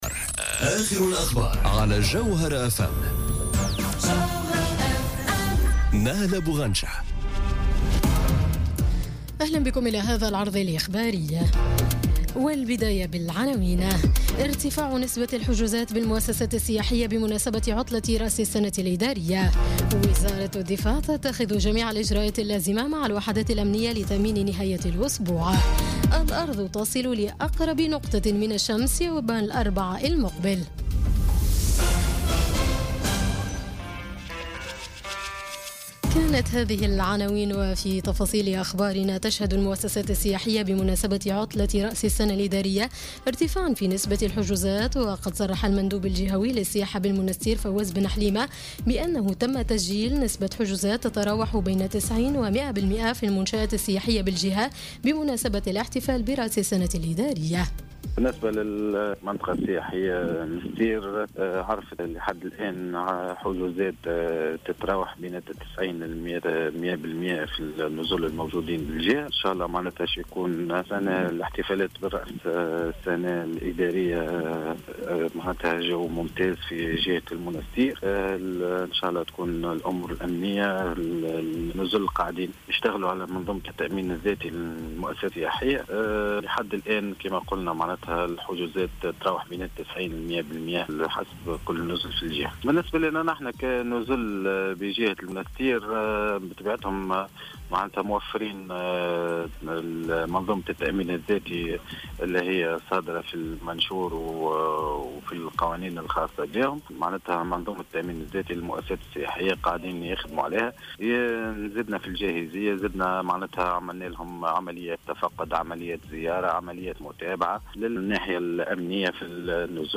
نشرة أخبار السابعة مساءً ليوم السبت 30 ديسمبر 2017